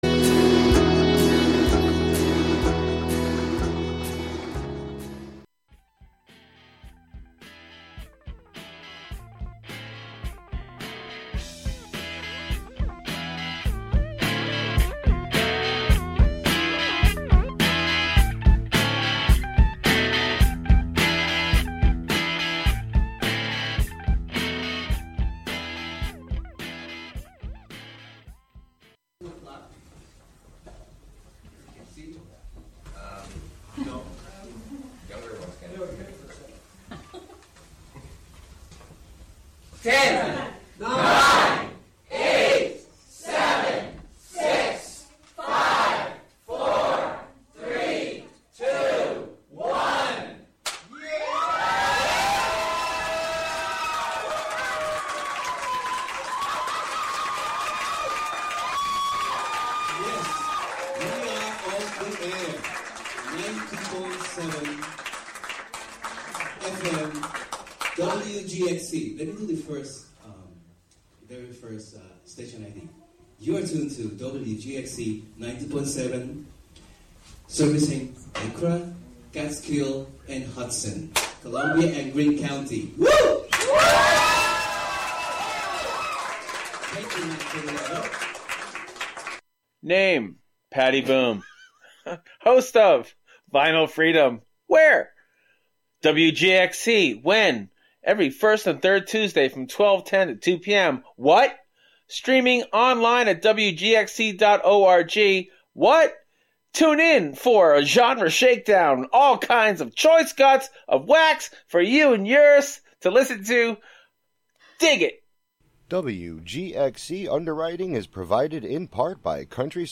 It also amplifies Indigenous music traditions to bring attention to their right to a sustainable future in the face of continued violence and oppression. We will explore the intersection of acoustic ecology, musical ethnography, soundscape studies, and restorative listening practices from the perspective of Indigenous musical TEK (Traditional Ecological Knowledge).